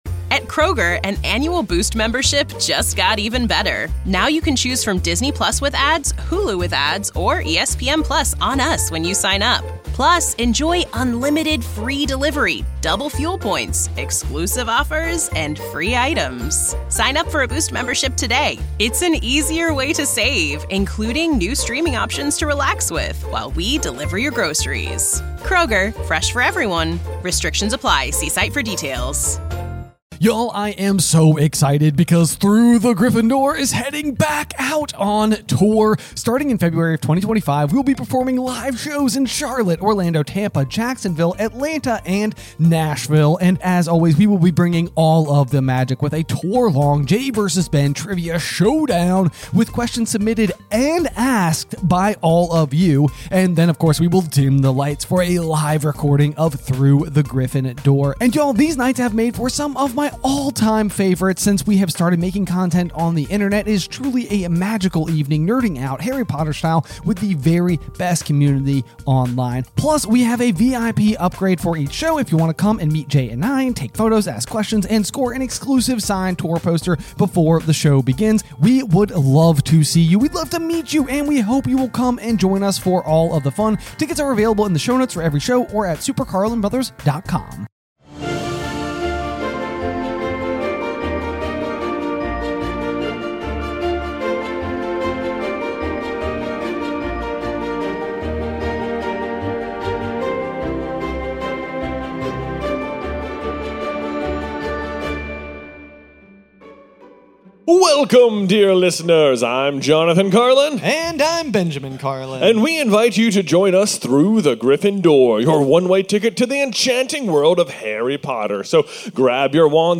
1 Live In San Diego - Chapter 1: The Riddle House | Goblet of Fire 1:07:10